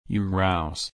/ˈɹaʊz/